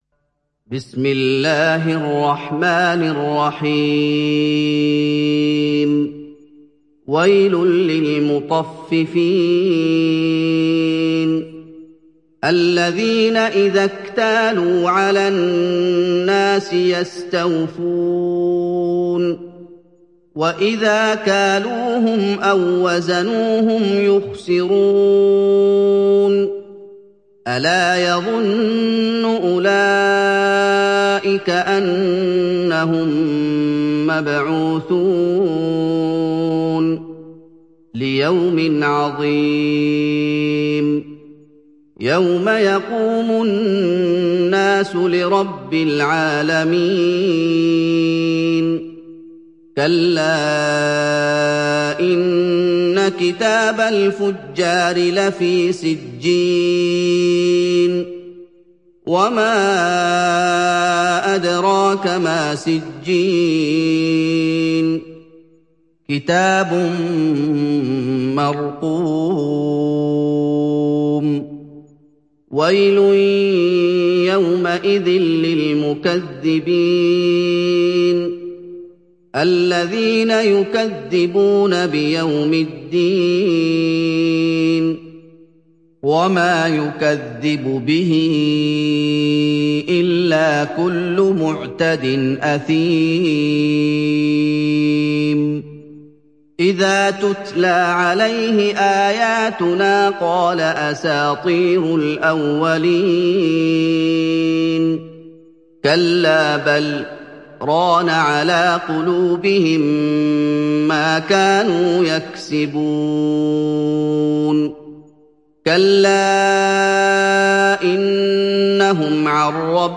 Surah Al Mutaffifin Download mp3 Muhammad Ayoub Riwayat Hafs from Asim, Download Quran and listen mp3 full direct links